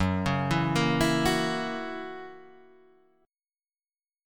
F#7 chord